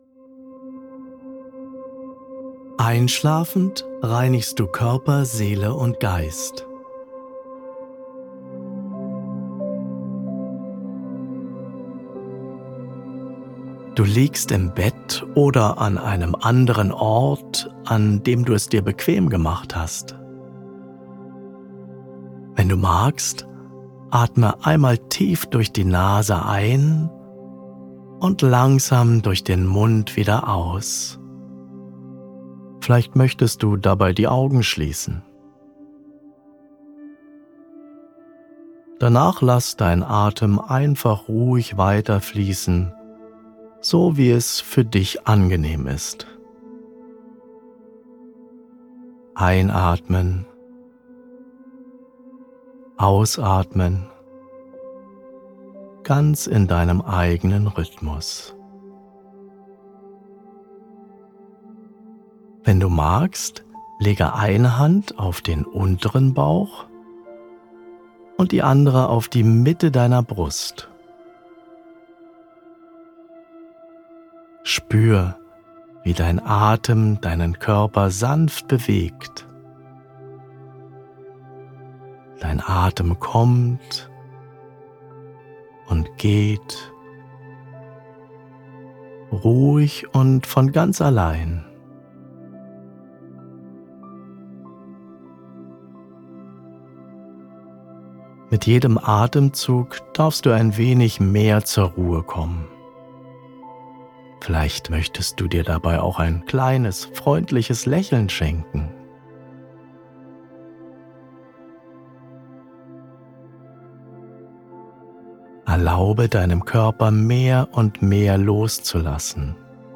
Meditative Reisen zur Entspannung und Heilung | Endlich gut schlafen!